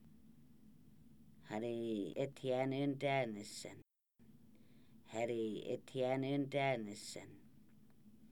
Individual audio recordings of Kaska words and phrases about emotions. This subset of the original How Are You Feeling Emotions Deck focuses on low energy states.